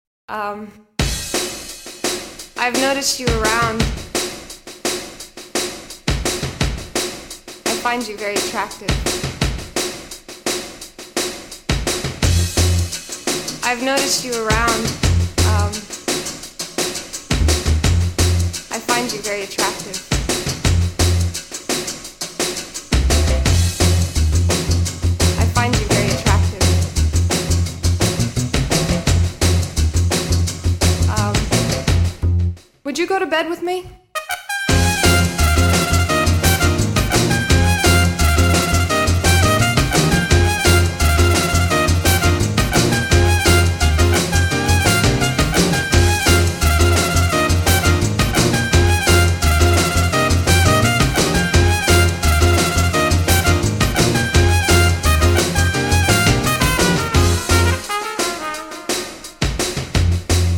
Pop
приятная, чувственная музыка